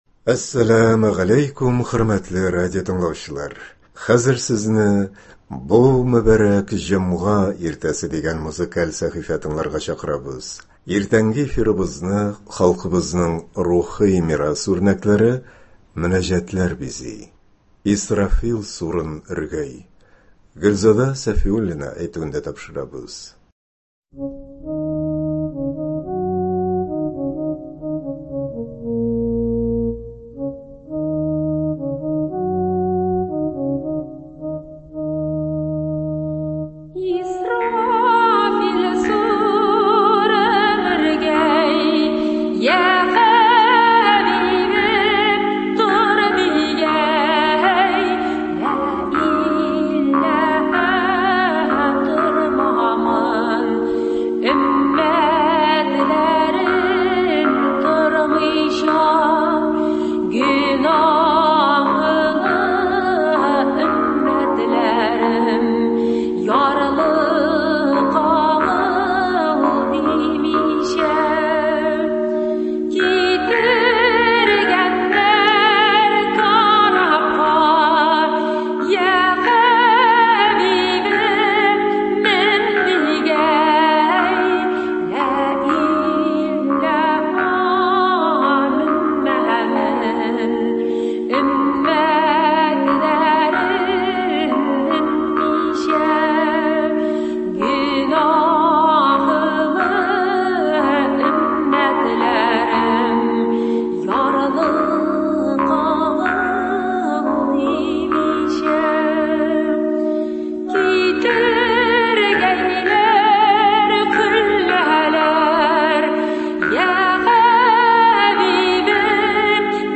Иртәнге эфирыбызны халкыбызның рухи мирас үрнәкләре — мөнәҗәтләр бизи.